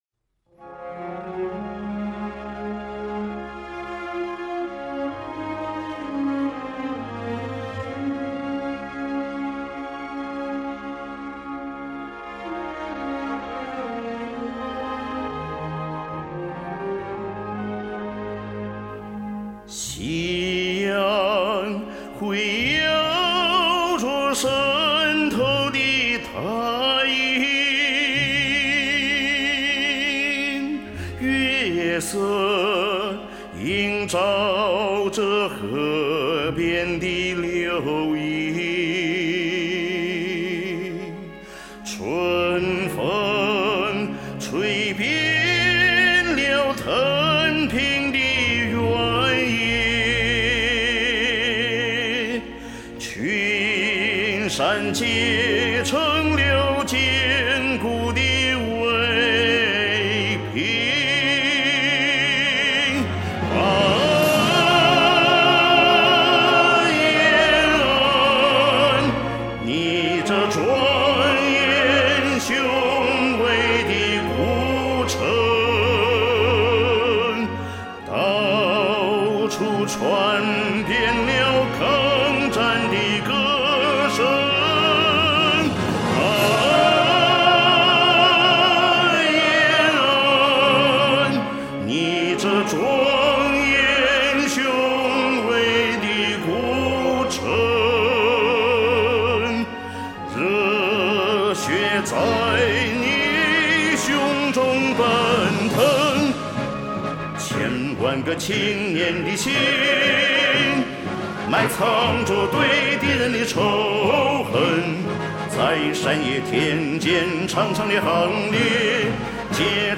昨天重唱,自我感觉还是有进步.